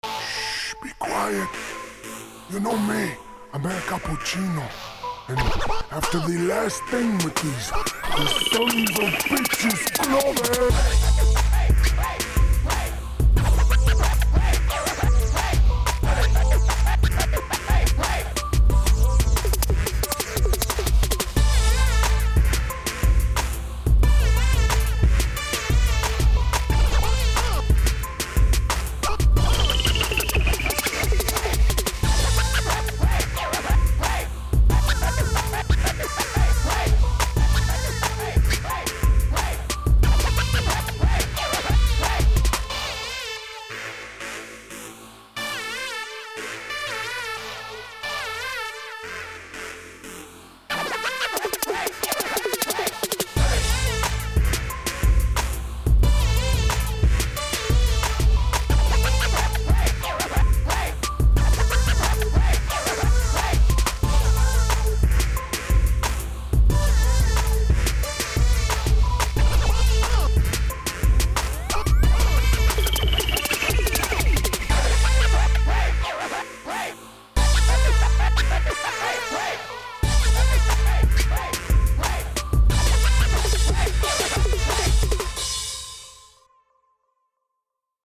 Скачать Минус
Стиль: Rap